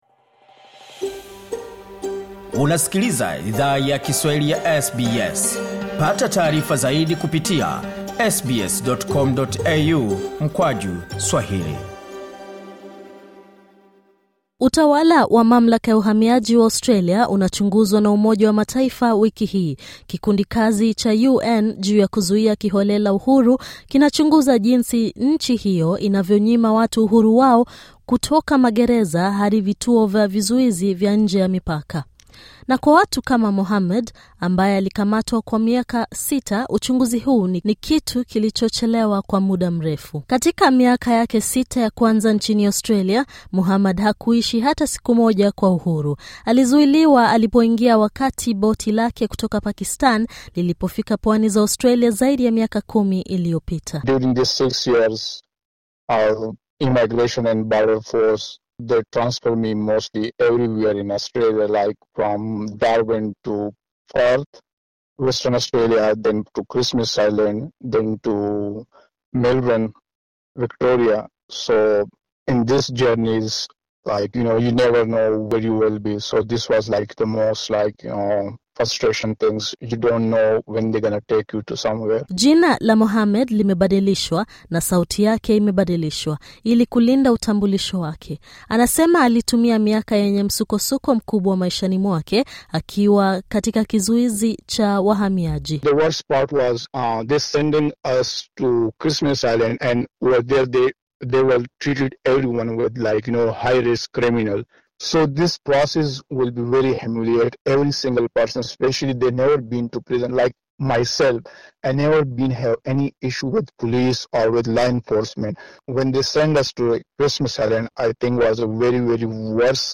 na sauti yake imebadilishwa, ili kulinda utambulisho wake.